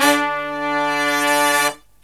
LONG HIT10-R.wav